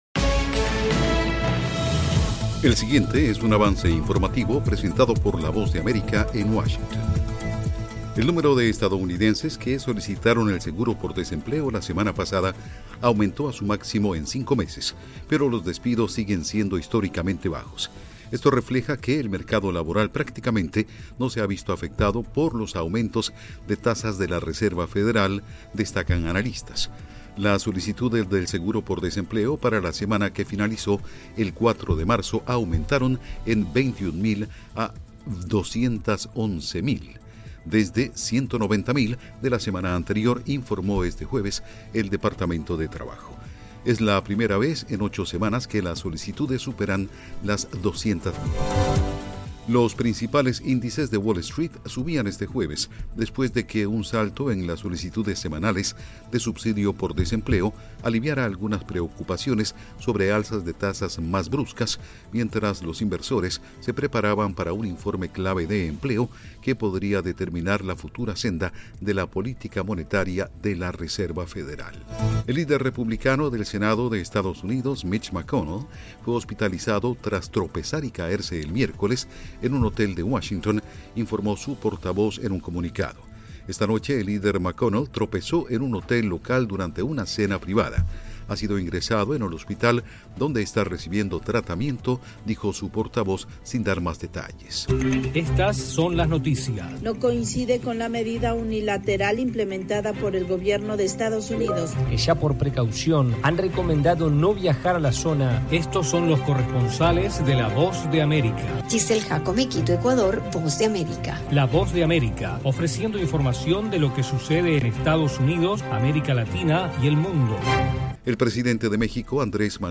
Avance Informativo 2:00 PM
El siguiente es un avance informativo presentado por la Voz de América en Washington.